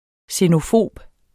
Udtale [ senoˈfoˀb ]